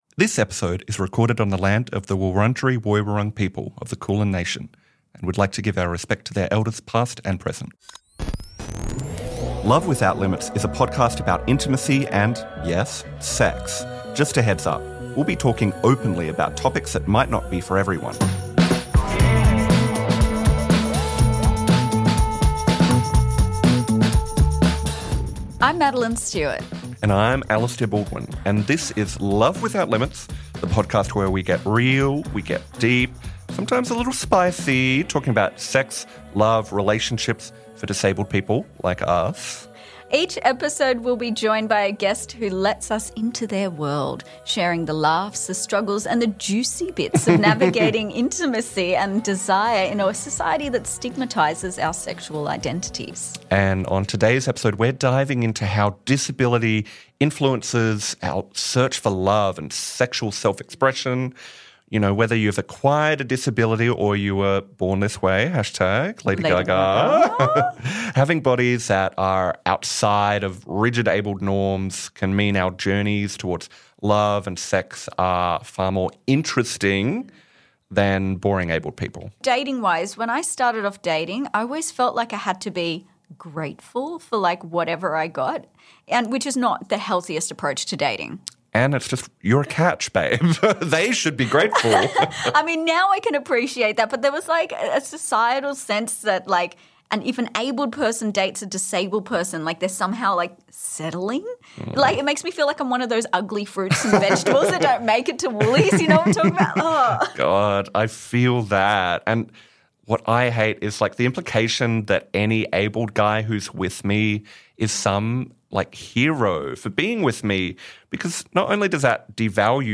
a wheelchair user who communicates via an assistive communication device